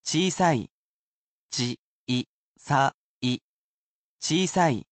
We have here with us the portable version of our computer robot friend, QUIZBO™ Mini, who will be here to help read out the audio portions.